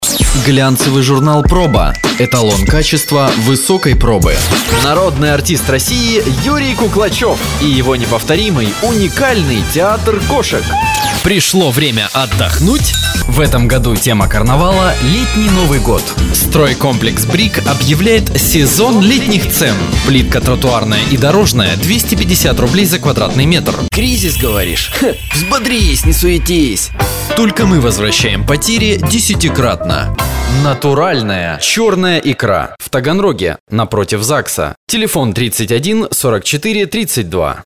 Универсальный диктор. реклама, объявления, озвучка!
Тракт: Behringer T1, ART VoiceChannel, ESI Juli@
Демо-запись №1 Скачать